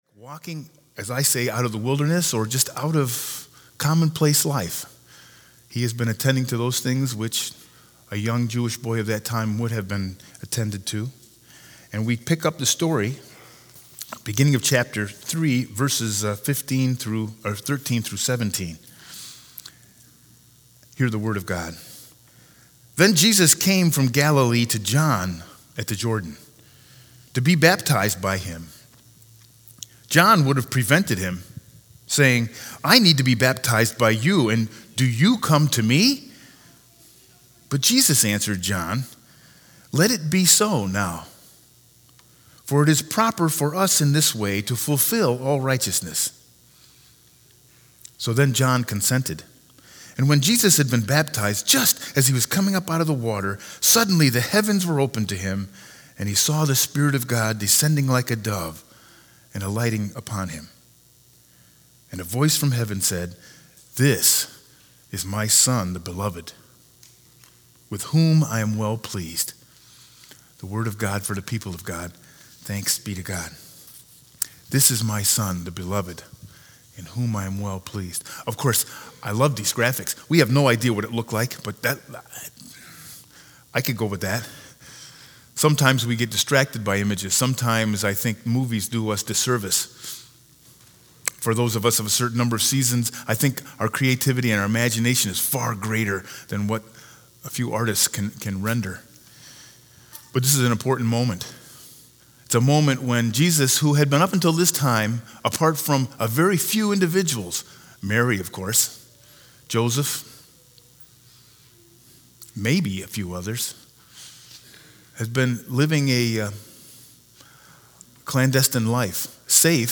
Sermon 1-12-20 With Scripture Lesson Matthew 3_13-17